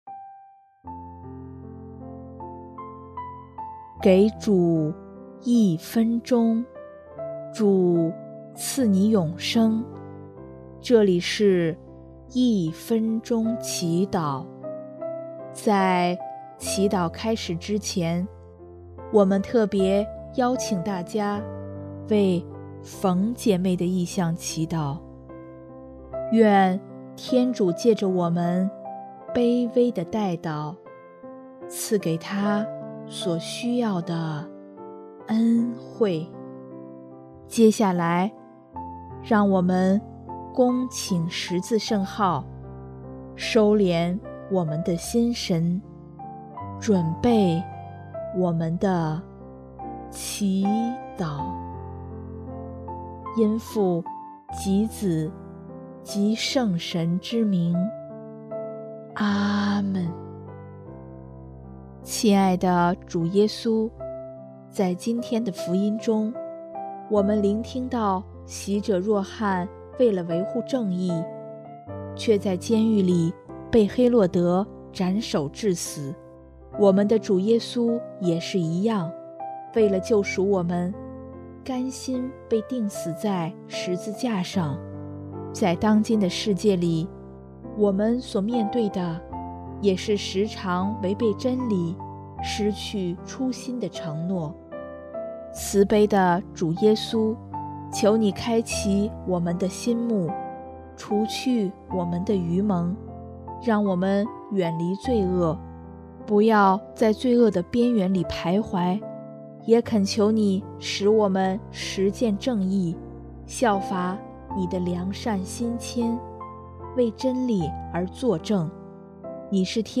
音乐： 第四届华语圣歌大赛参赛歌曲《归正》